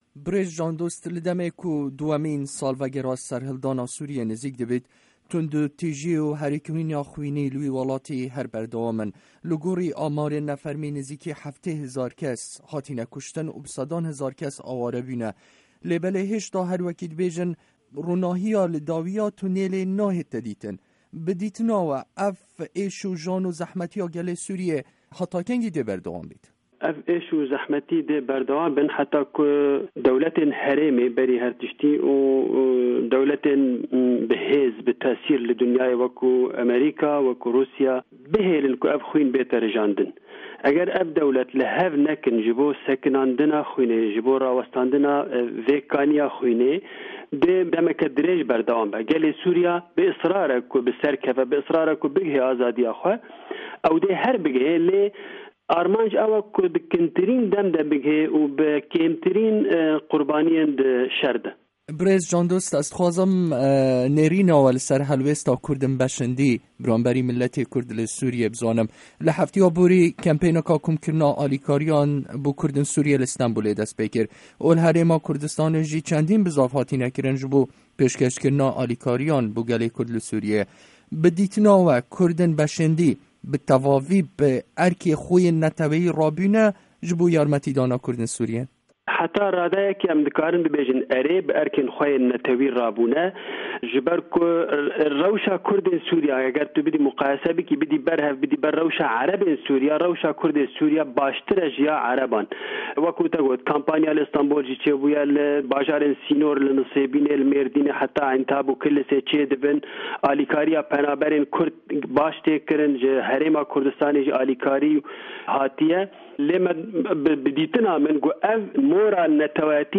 Hevpeyvîn bi birêz Jan Dost re